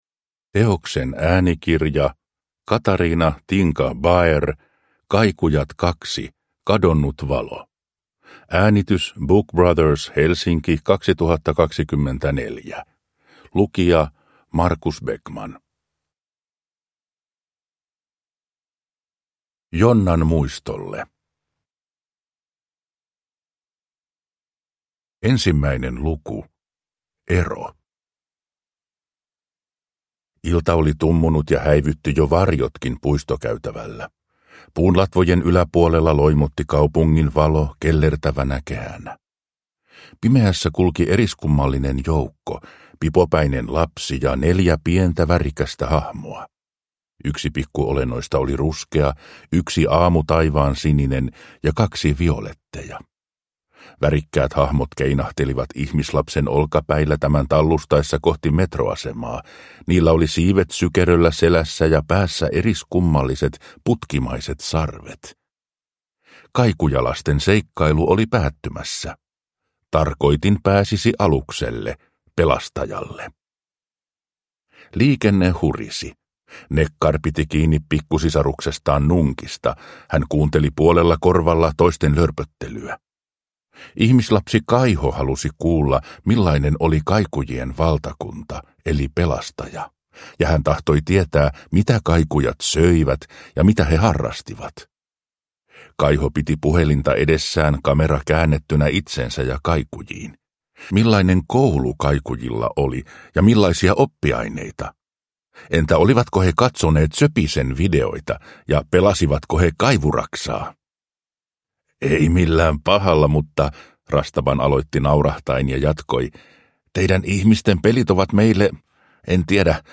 Kadonnut valo – Ljudbok